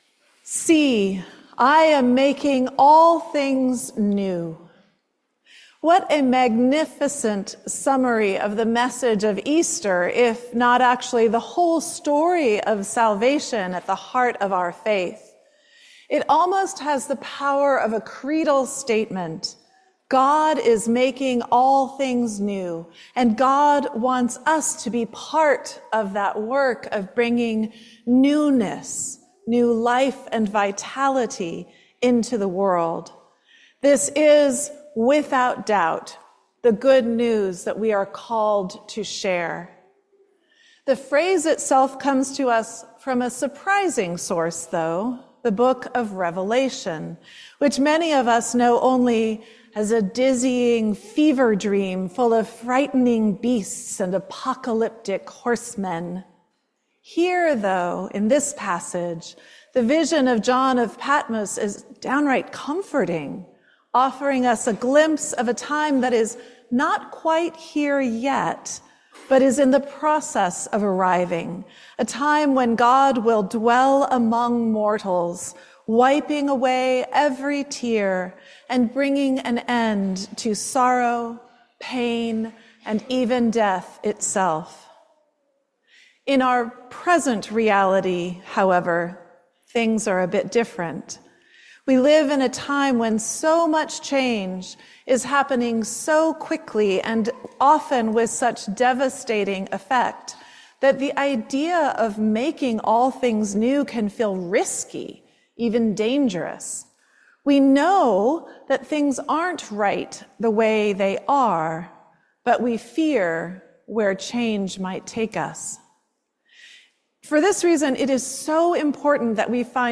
Sermon on May 18, 2025“Making All Things New